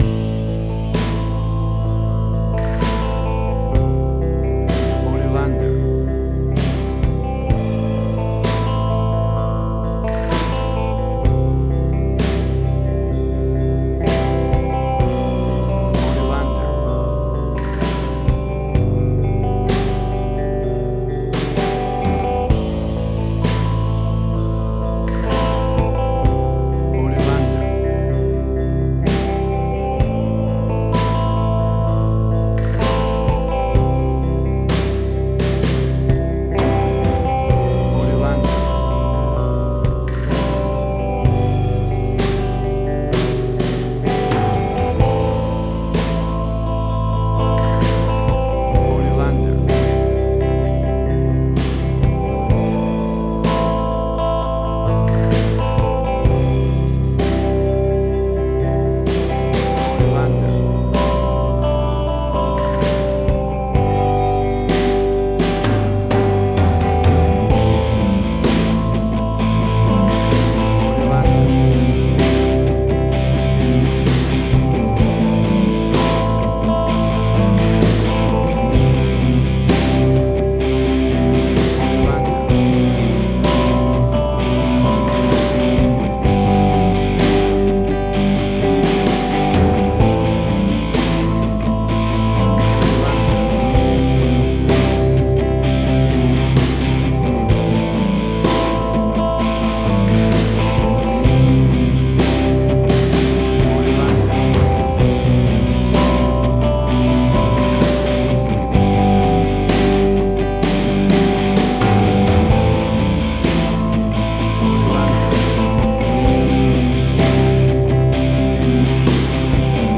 Hard Rock, Similar Black Sabbath, AC-DC, Heavy Metal.
Tempo (BPM): 64